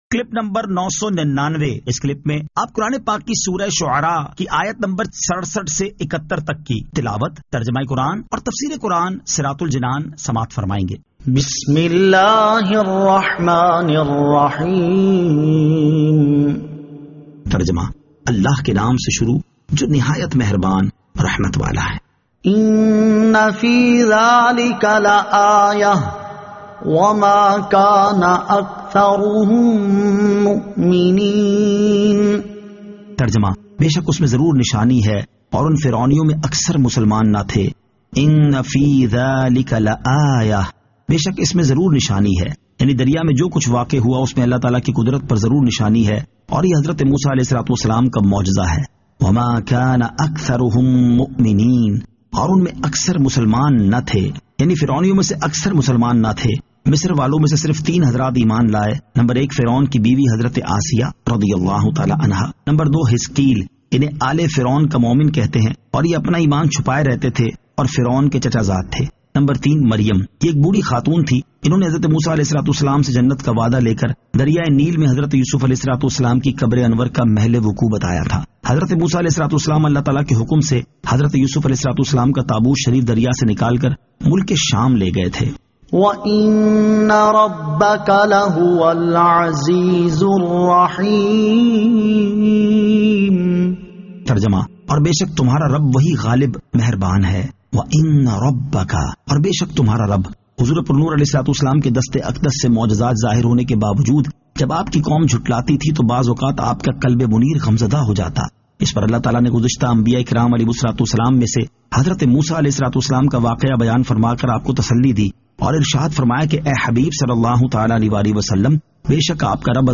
Surah Ash-Shu'ara 67 To 71 Tilawat , Tarjama , Tafseer